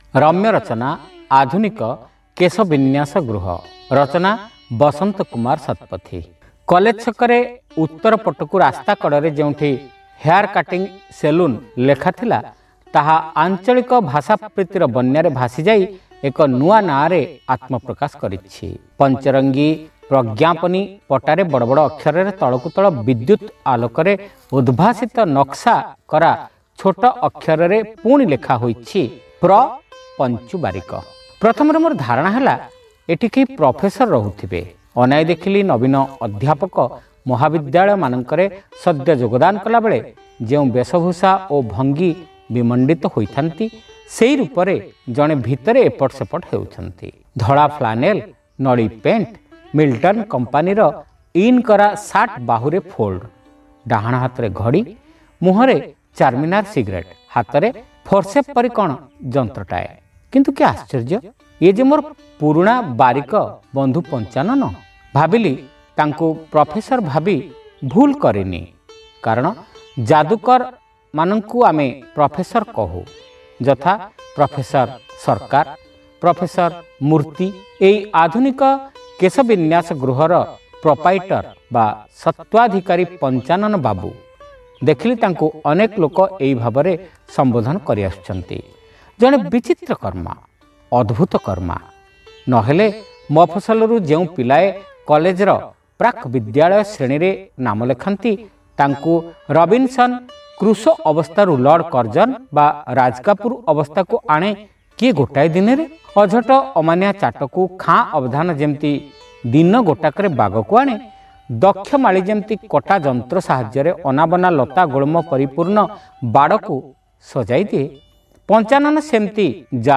Audio Story : Adhunika Kesha Binyasa Gruha